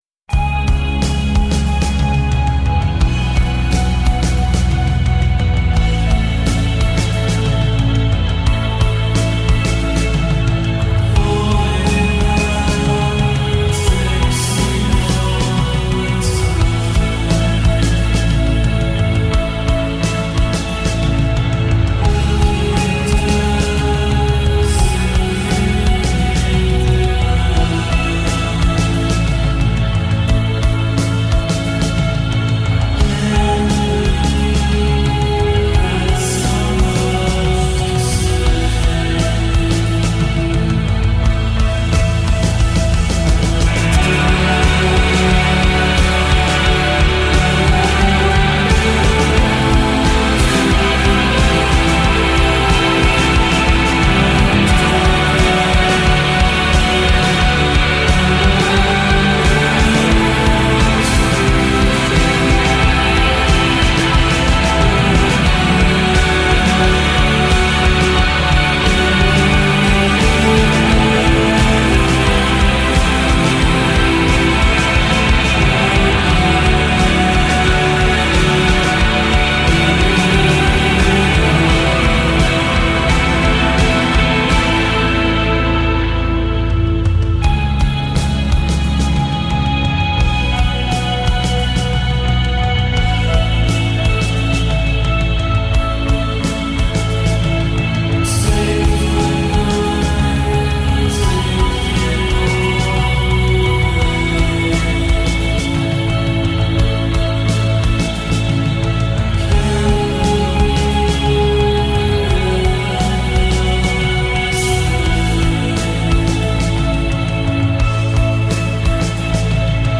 In Session April 21, 1991
a dose of vintage ethereal
vocals, guitars, keyboards
drums, guitars, electronics